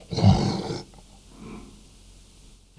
PixelPerfectionCE/assets/minecraft/sounds/mob/polarbear/idle1.ogg at mc116